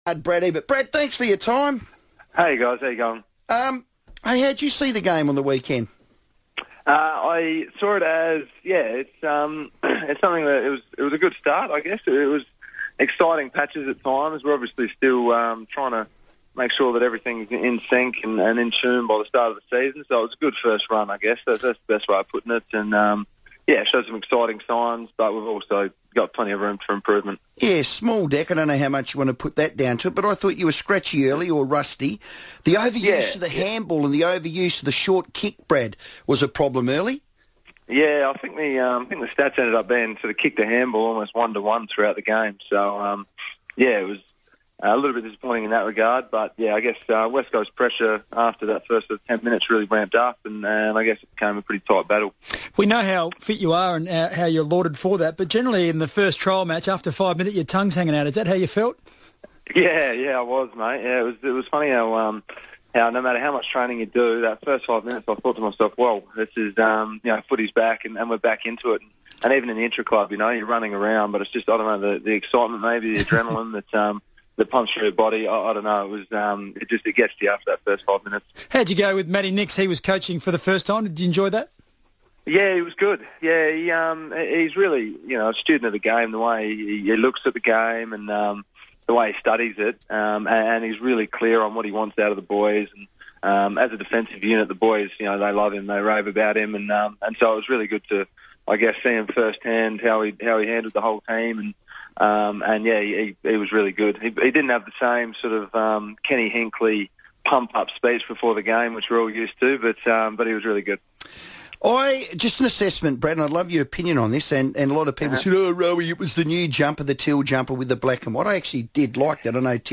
Brad Ebert FIVEaa interview - Tuesday 10th March, 2015